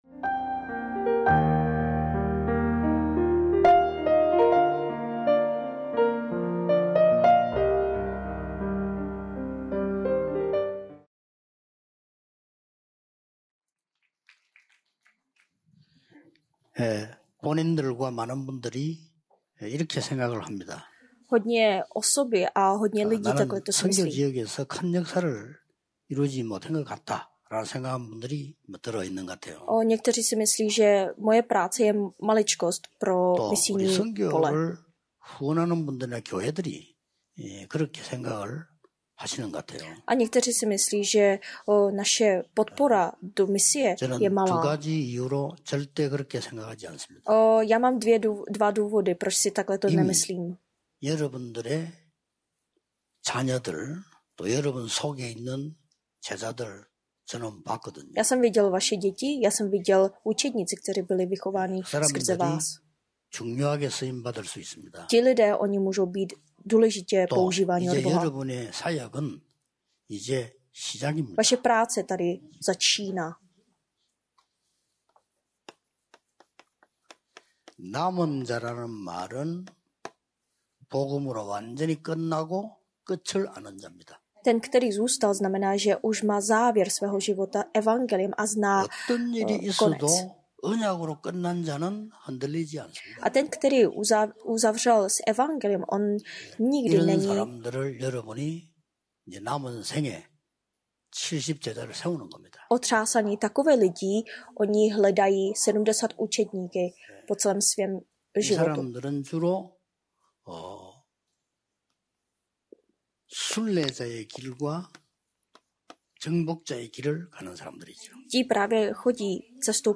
237 zemí a odpověď 24h 1., 2. a 3. RUTC (10) 3. lekce světové misijní konference 10 tajemství a misionář (Gen 37,1-11)